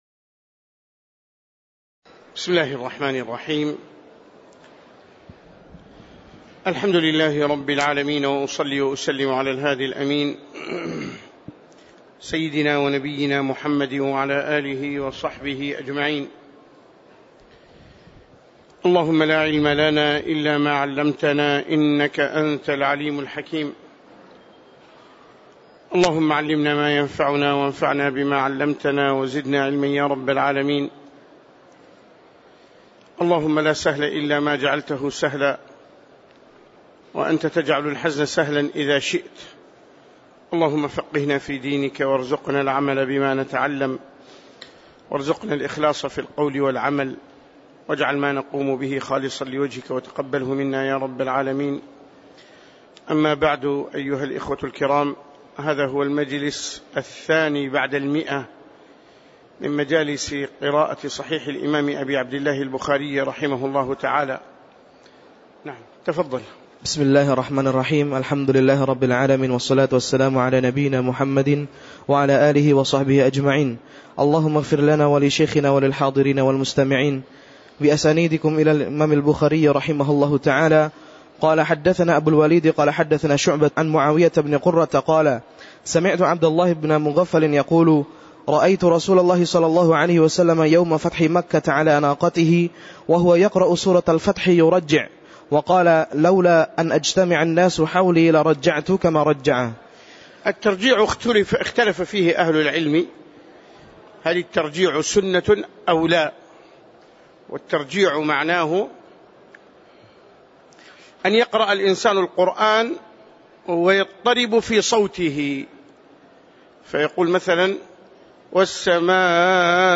تاريخ النشر ٢١ رجب ١٤٣٨ هـ المكان: المسجد النبوي الشيخ